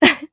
Seria-Vox_Happy1_jp.wav